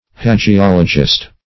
Hagiologist \Ha`gi*ol"o*gist\ (-[-o]*j[i^]st), n.